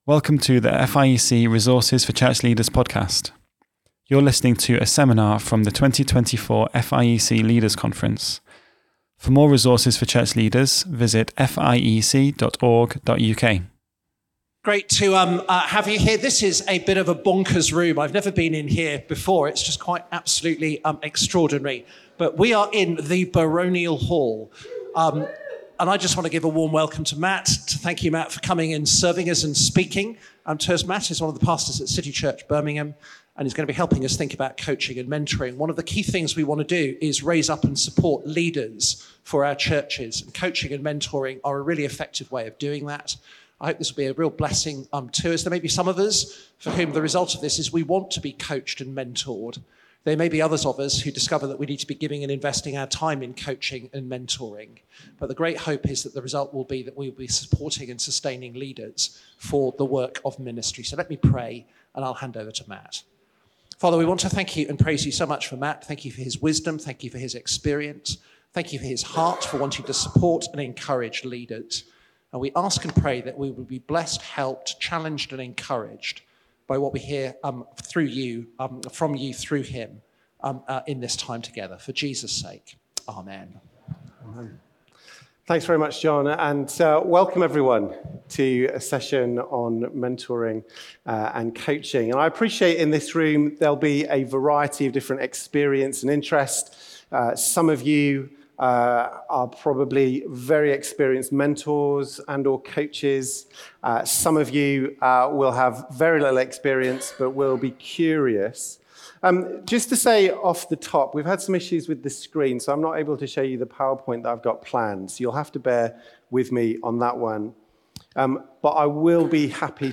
How can we embrace a right view of coaching to develop our own capabilities and release others to serve and go? A seminar from the 2024 Leaders' Conference.